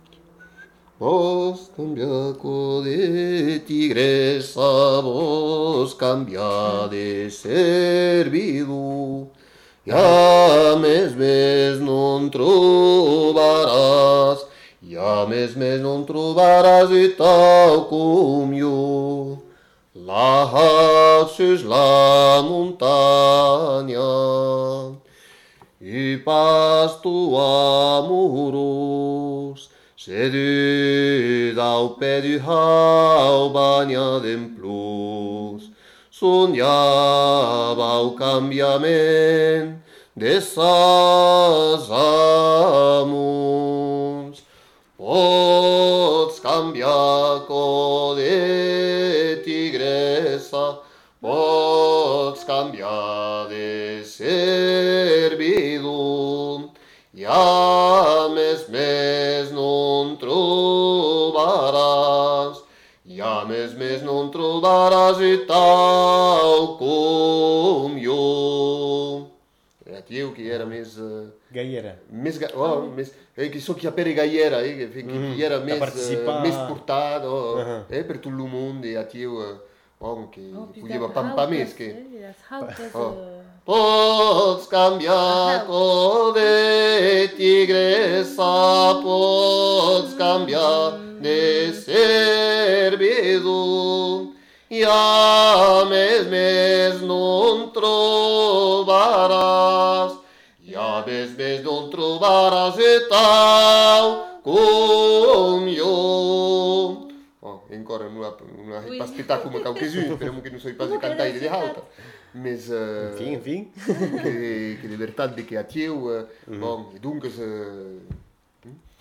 Aire culturelle : Bigorre
Lieu : Ayzac-Ost
Genre : chant
Effectif : 2
Type de voix : voix d'homme ; voix de femme
Production du son : chanté